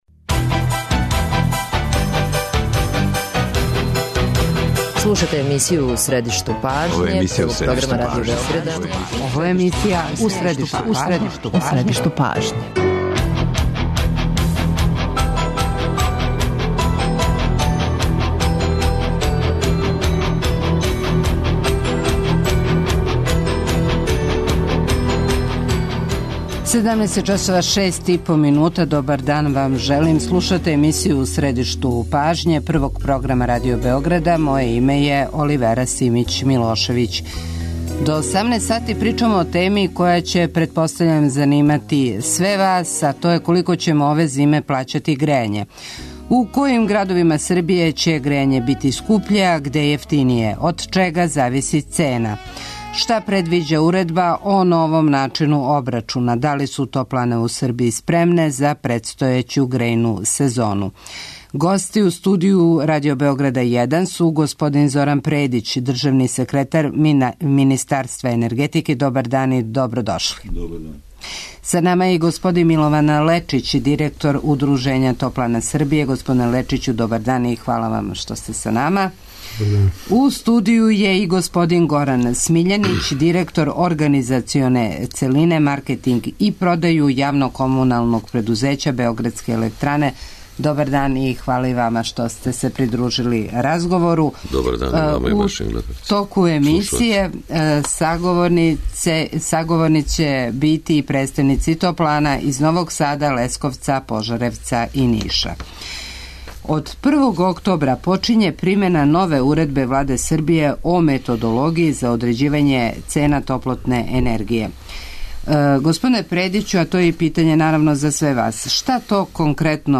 Саговорници емисије су и представници топлана из Београда, Новог Сада, Ниша, Лесковца и Пожаревца.